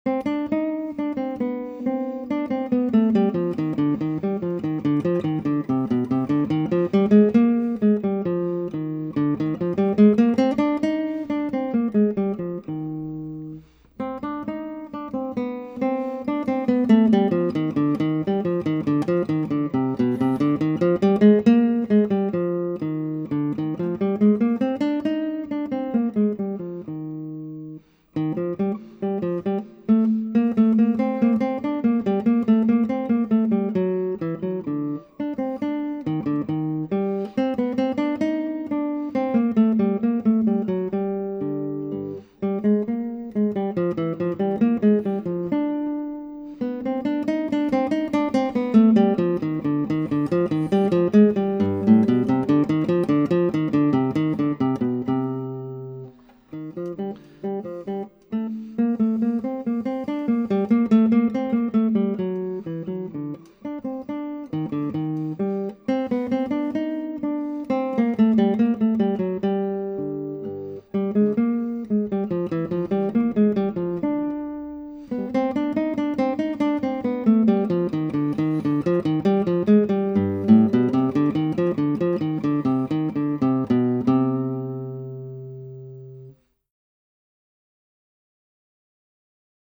Methods - Guitar